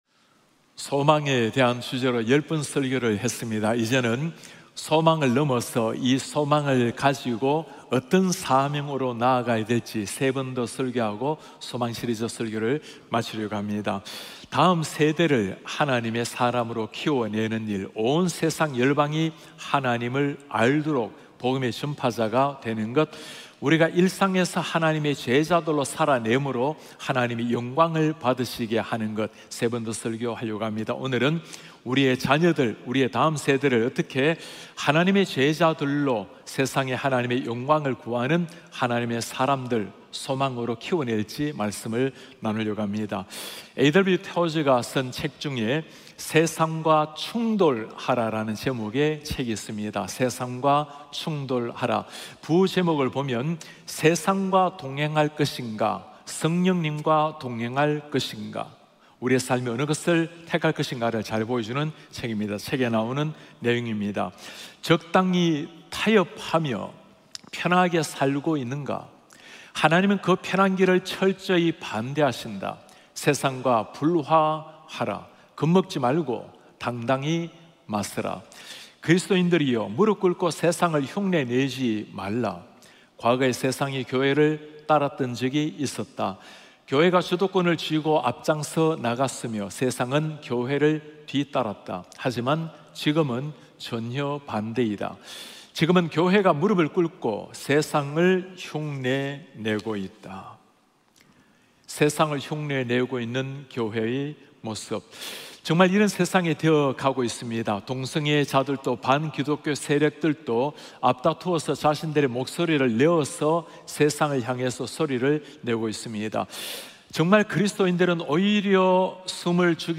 예배: 주일 예배